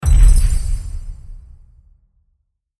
attack_box.mp3